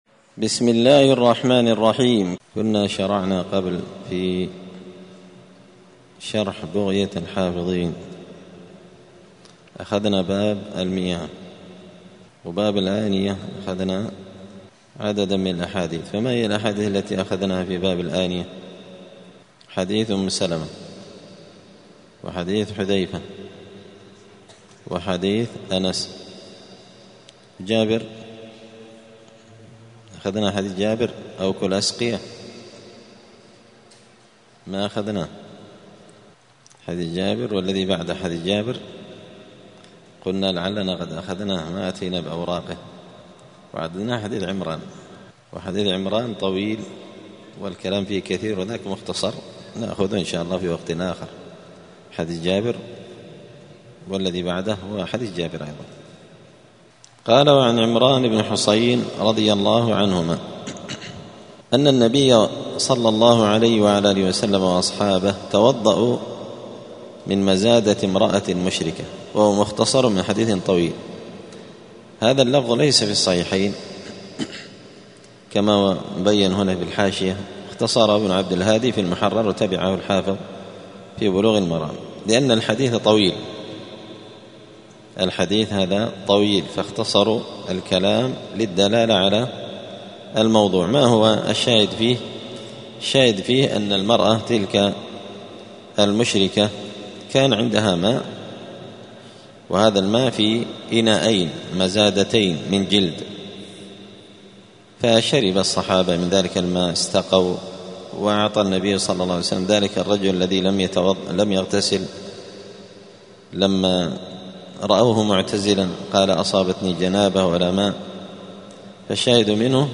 دار الحديث السلفية بمسجد الفرقان بقشن المهرة اليمن
*الدرس الحادي عشر (11) {باب الآنية حكم استعمال أواني الكفار…}*